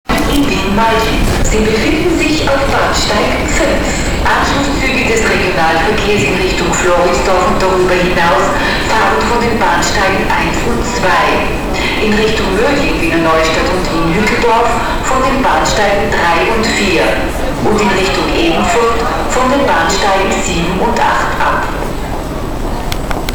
WnMl_hlaseni_eska.WMA